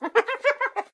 Add hyena sounds
sounds_hyena_01.ogg